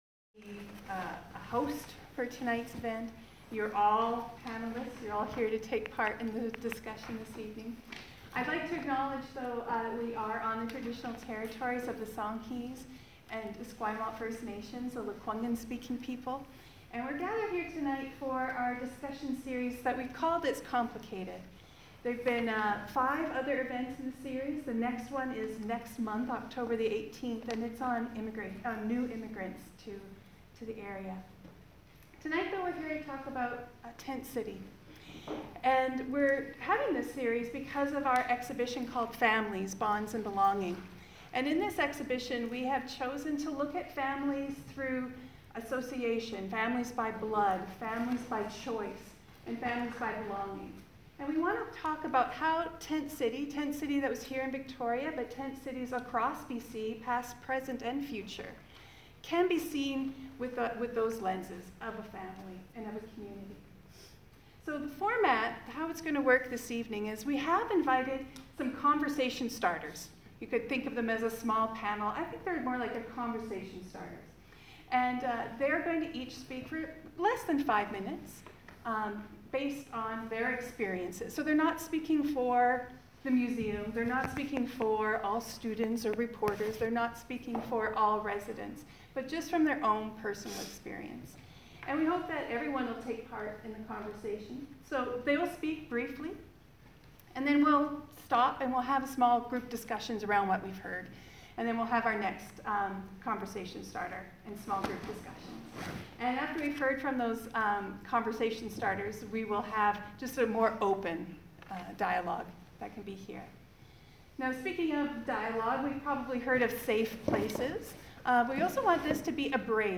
The Royal BC Museum held an event last September which the The Martlet kindly recorded.